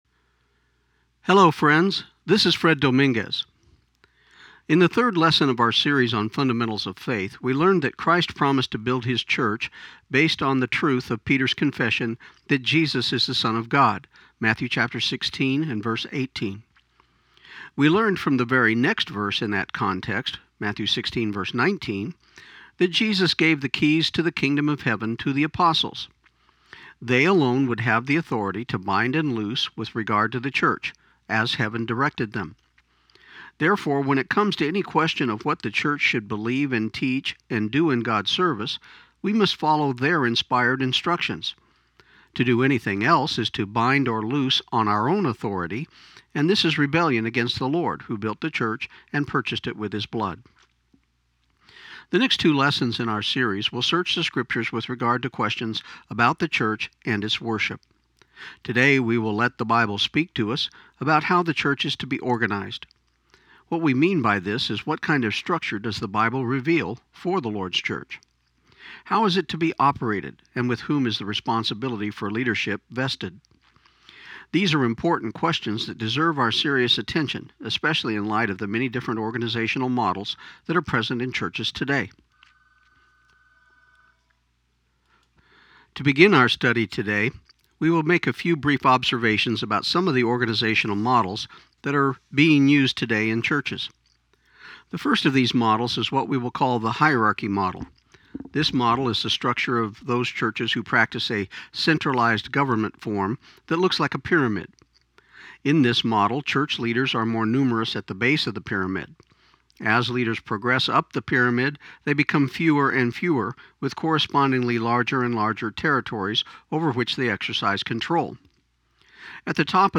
This program aired on KIUN 1400 AM in Pecos, TX on January 12, 2015